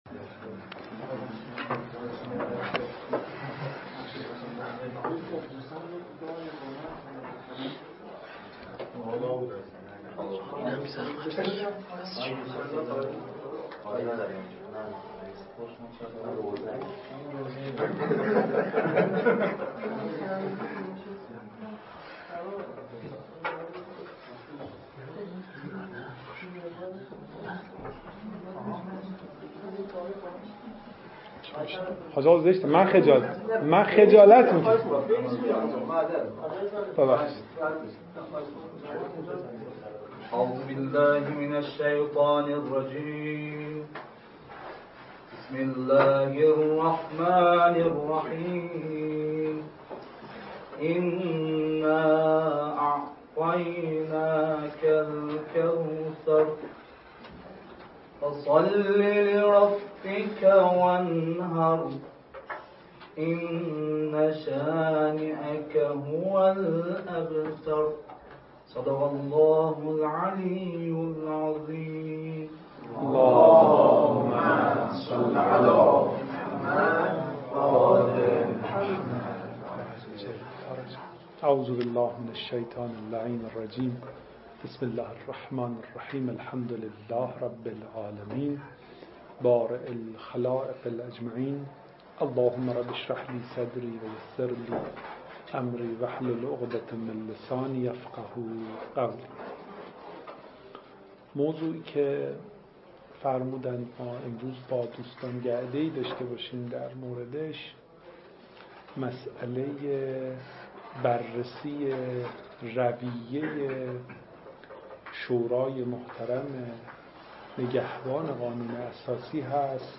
نشست علمی
همچنین این نشست علمی به صورت پخش زنده از طریق کانال ایتای مدرسه امام حسین (علیه السلام) ارائه شد.